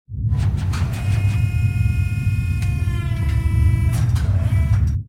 repair3.ogg